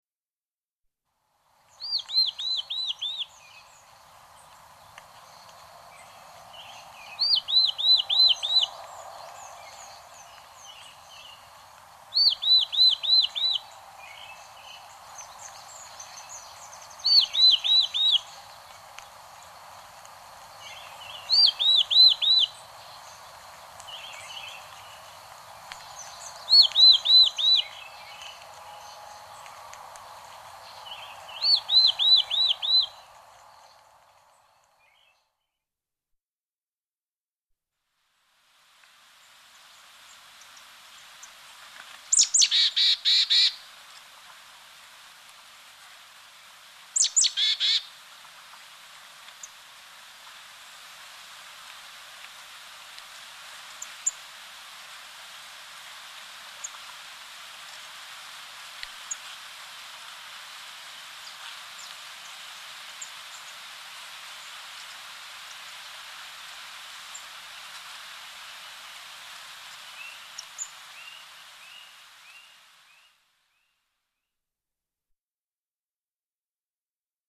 「さえずり」は春の繁殖に向けて出すオスの声なので、いわゆるラブソング。
↓ハシブトガラさえずり
さえずりの声が違いますね。
hashibutogara.mp3